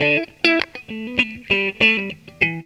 GTR 52 EM.wav